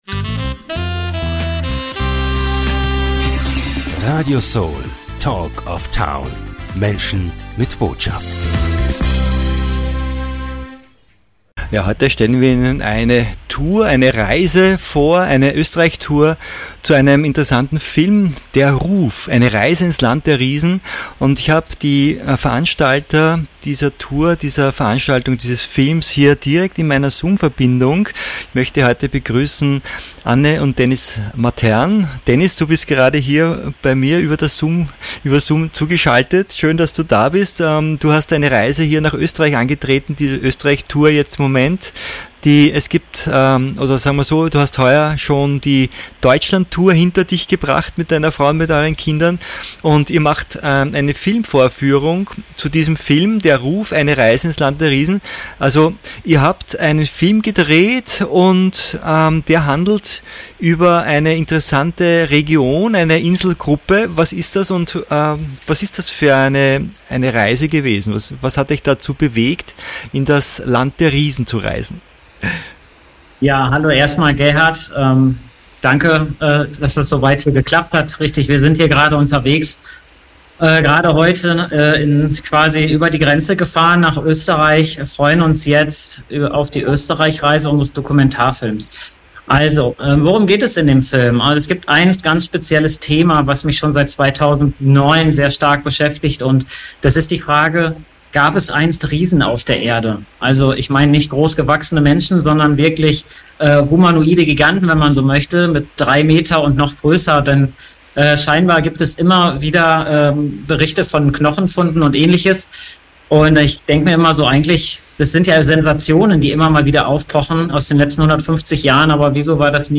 Eine spannende Dokumentation mit Interviews und noch nie veröffentlichten Szenen wie aus einer anderen Welt.